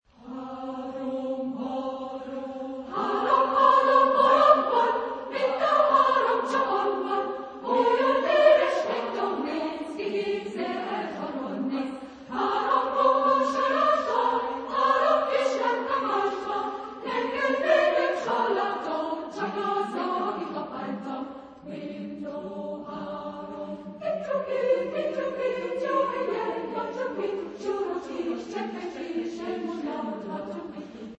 Epoque: 20th century
Type of Choir: SA  (2 women voices )